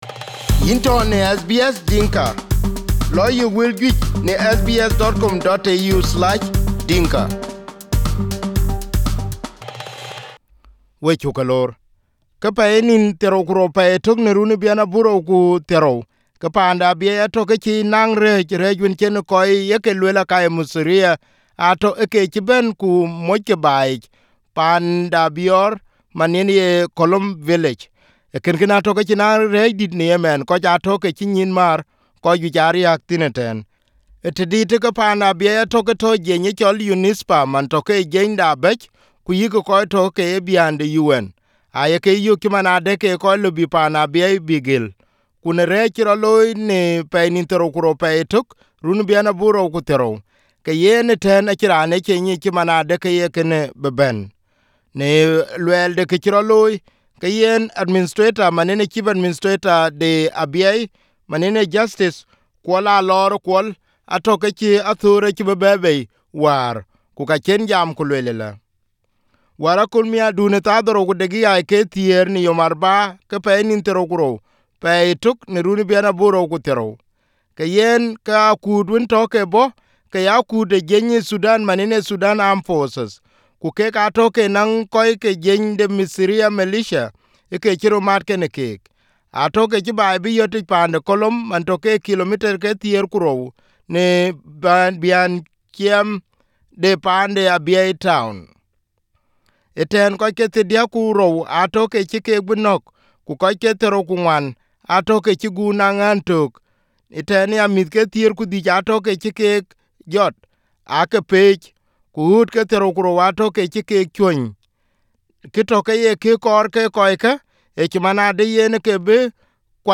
interviewed on SBS Dinka this morning